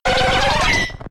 Cri d'Aéromite K.O. dans Pokémon X et Y.